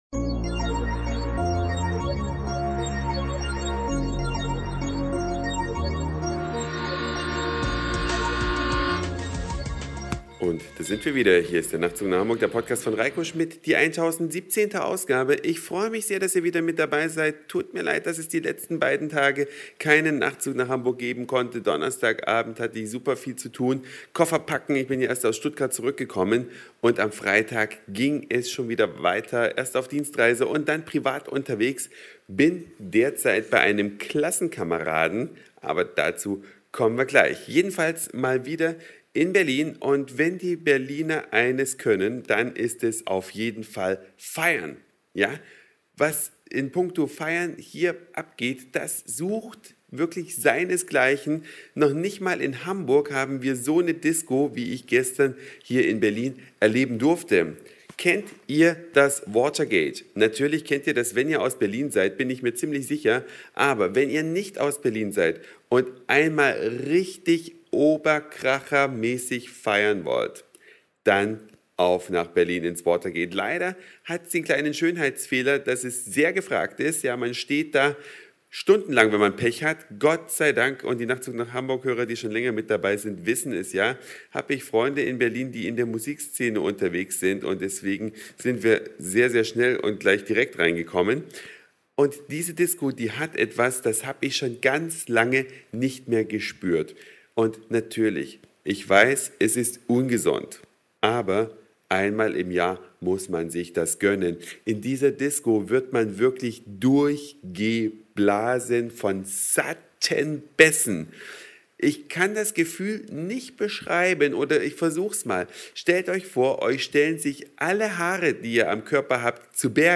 Eine Reise durch die Vielfalt aus Satire, Informationen, Soundseeing und Audioblog.
Die Bässe bewegen den Körper, stinkend abfeiern in Berlin.